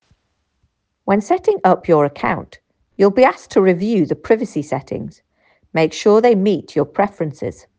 7. GB accent: Privacy